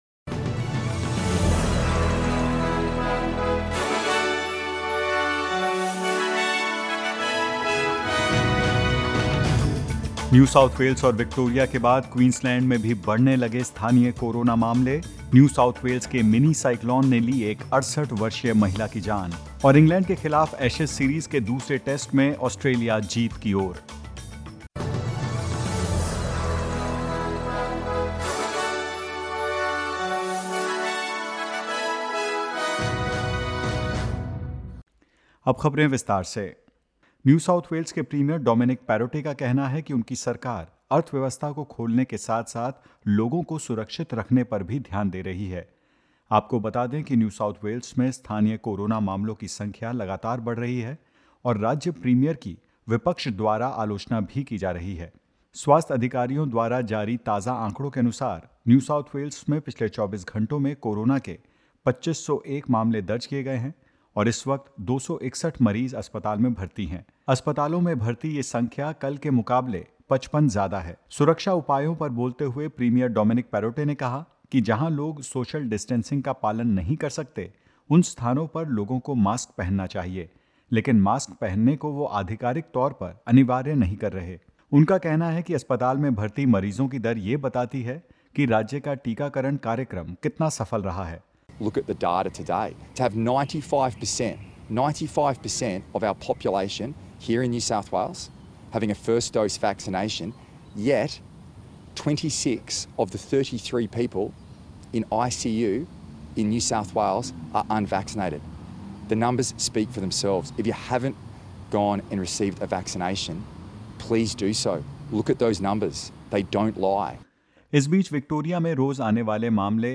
In this latest SBS Hindi news bulletin: New South Wales Premier Dominic Perrottet says people should wear face masks indoors; Queensland registers 59 COVID-19 infections and more.